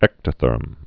(ĕktə-thûrm)